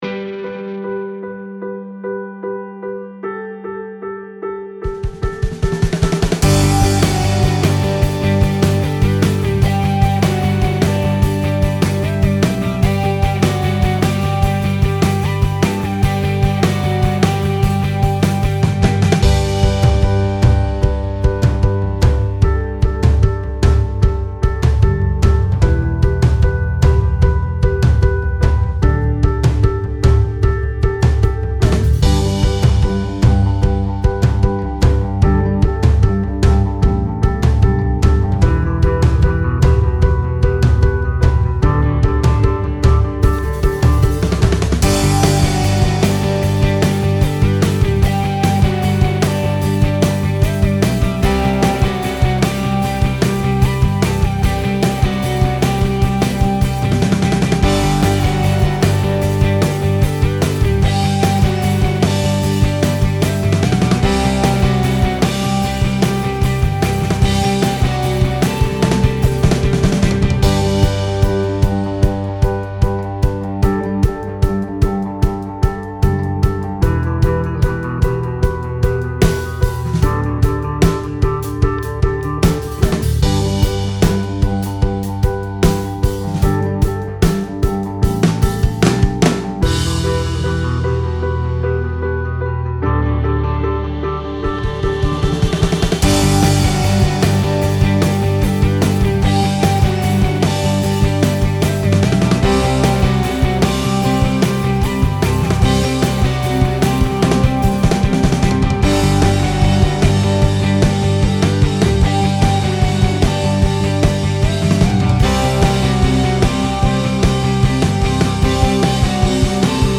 INDIE ROCK - INSTRUMENTALS